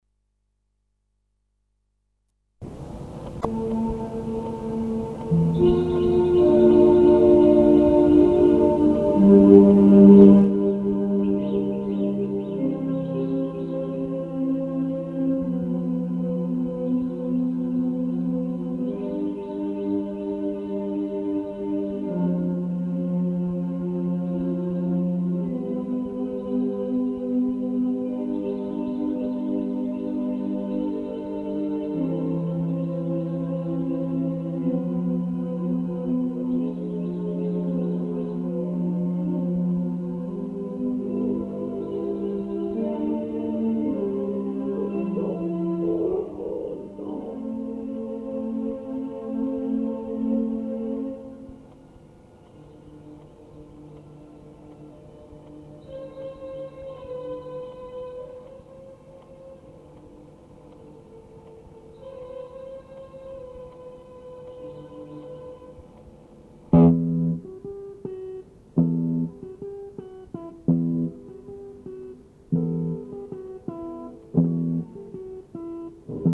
Запись крайне трушная...
keyboard, percussions, vocal, backvocal.
guitar, vocal, backvocal.